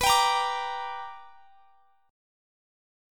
Listen to BmM7#5 strummed